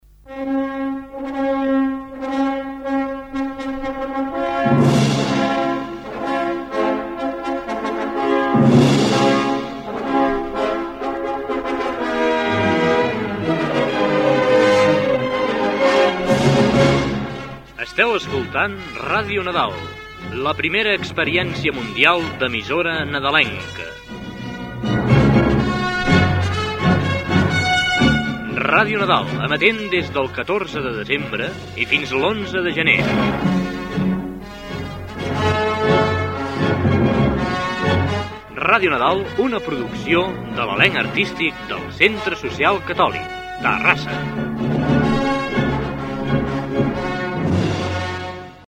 Identificació, freqüència i inici de l'emissió inaugural.
Identificació de l'emissora.
FM
Primer dia d'emissió